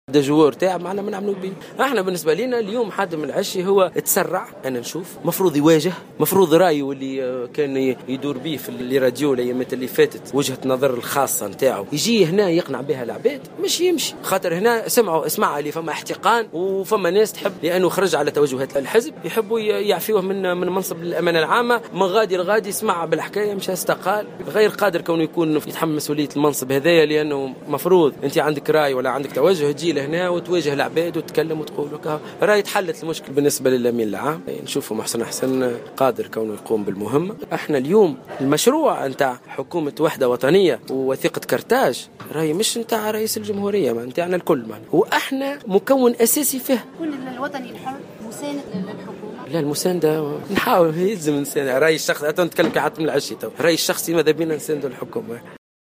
وأبرز الرياحي، على هامش أعمال المجلس الوطني الاستثنائي الذي يتواصل الليلة بالحمامات، أن الإتحاد الوطني الحر هو مكون من مكونات الائتلاف الحاكم وما يزال في الحكم حتى وإن لم يتحصل على حقائب وزارية.